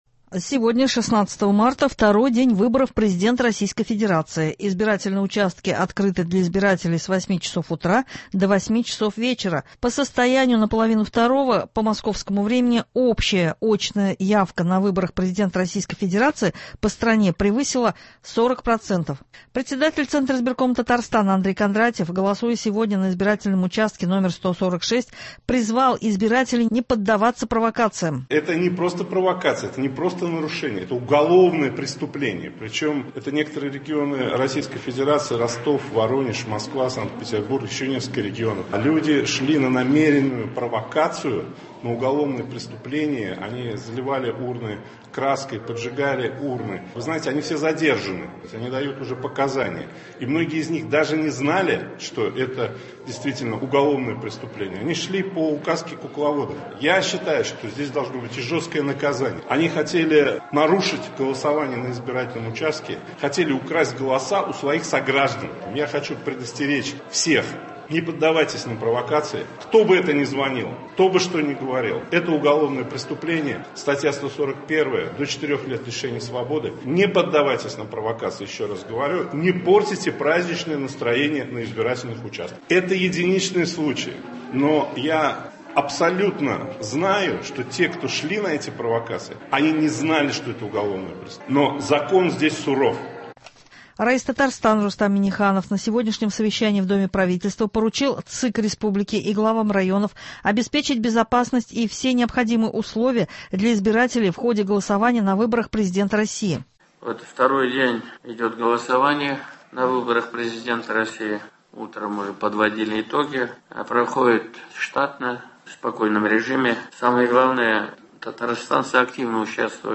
Утренний выпуск.
Новости (16.03.24)